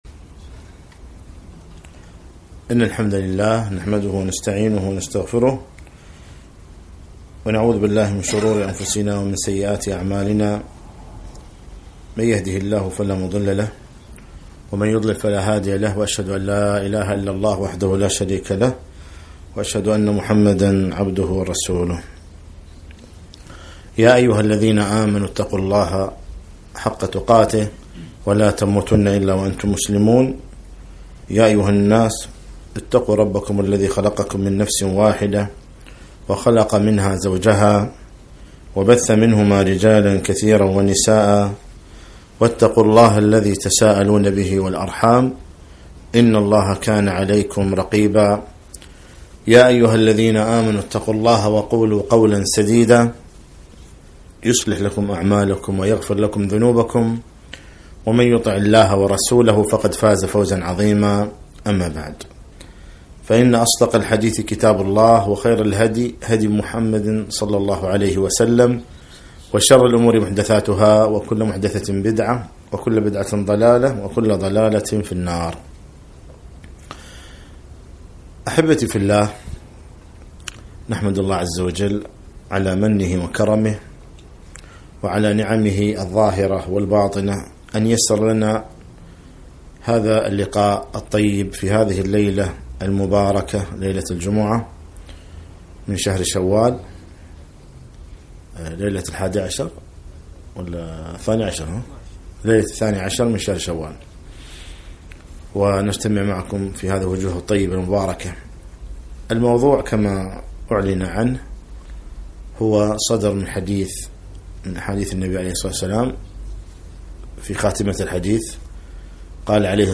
محاضرة - كل الناس يغدو